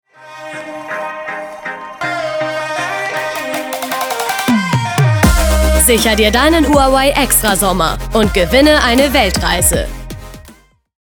dunkel, sonor, souverän, hell, fein, zart, markant, sehr variabel
Mittel minus (25-45)
Norddeutsch